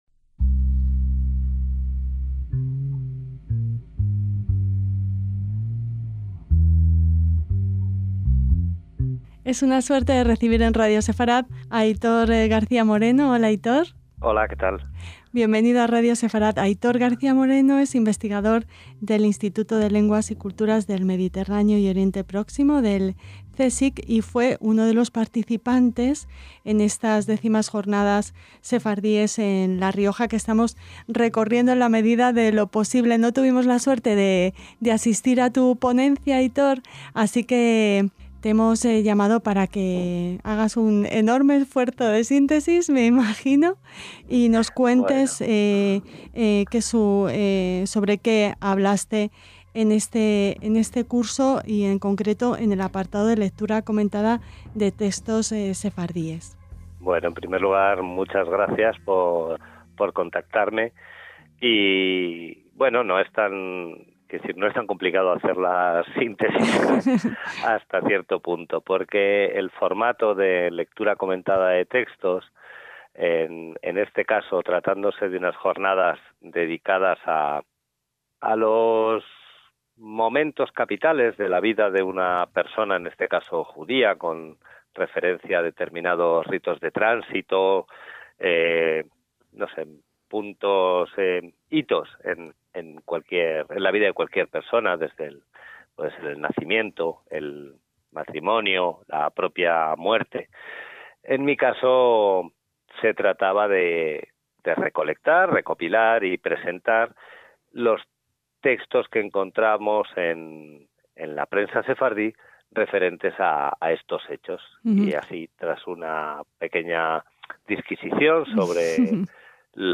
DESDE LAS X JORNADAS SEFARDÍES EN LA RIOJA – Un vaso de cerveza, dos manzanas...se subastaban en una rifa benéfica neoyorkina comentada en un medio de prensa en judeoespañol.